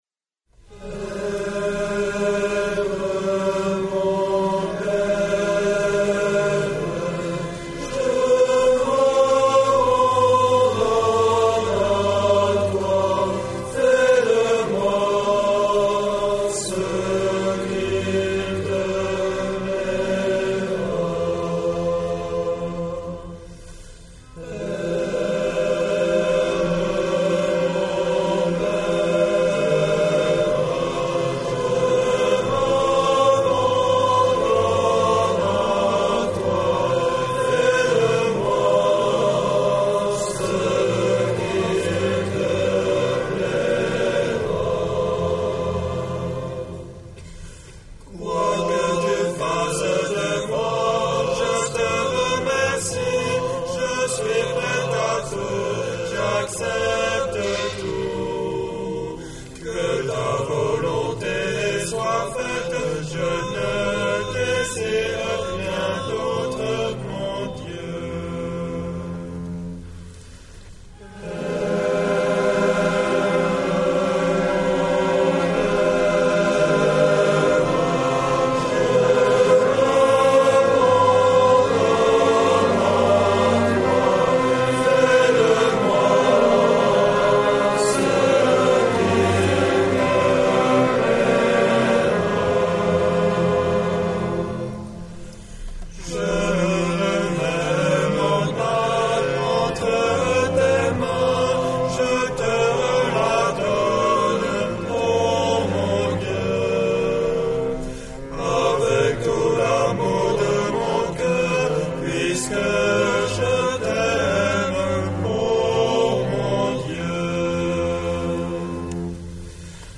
Chants scouts